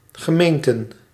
Since 1 January 2023, there have been 342 regular municipalities (Dutch: gemeenten [ɣəˈmeːntə(n)]